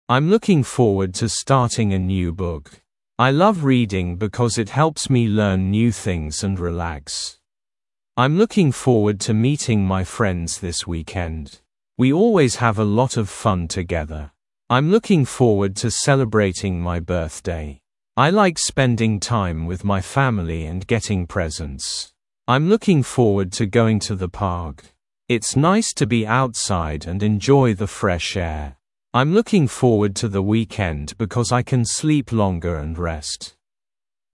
Произношение: